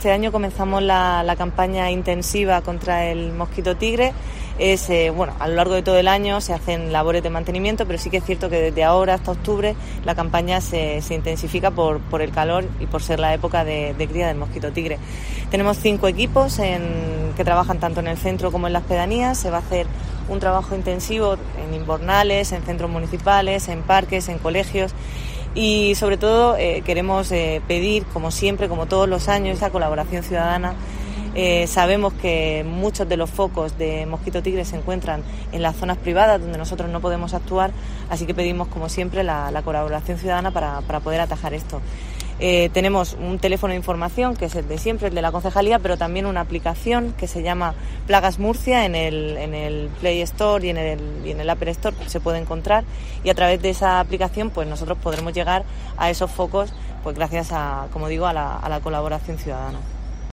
Esther Nevado, concejala de Salud y Transformación Digital